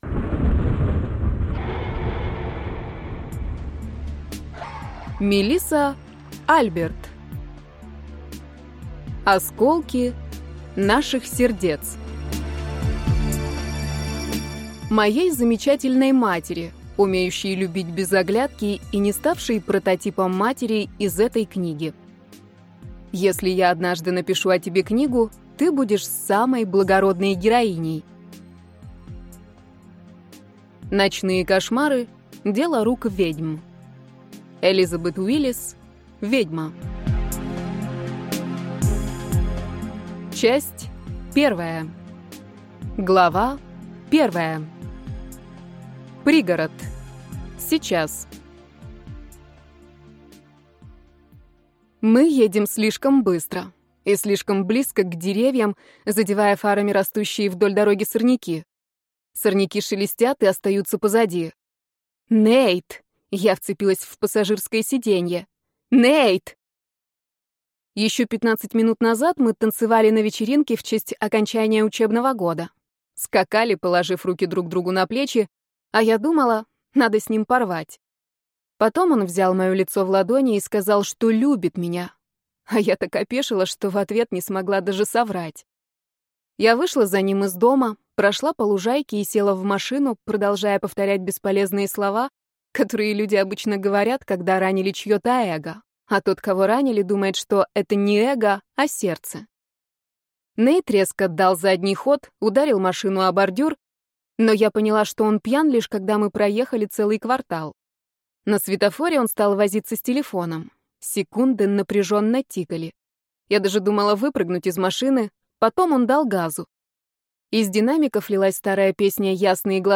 Аудиокнига Осколки наших сердец | Библиотека аудиокниг